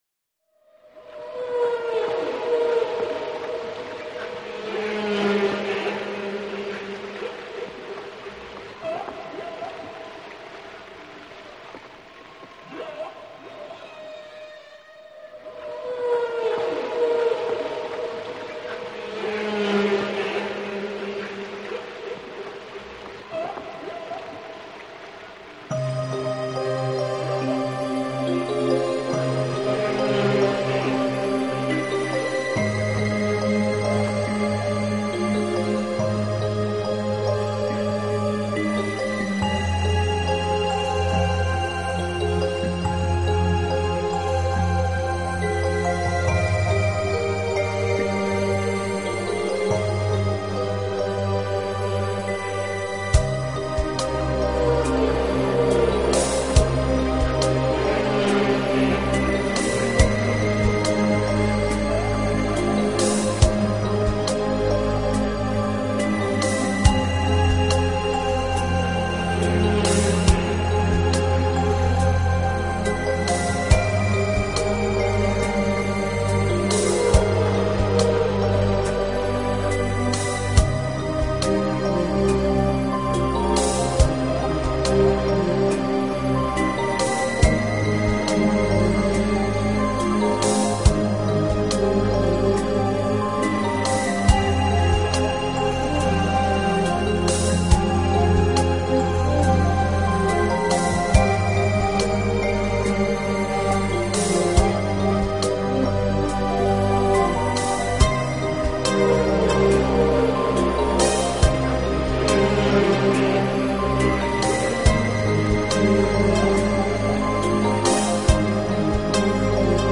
【纯音乐】
整张专辑充斥着海浪的咆 哮，鲸鱼的呢喃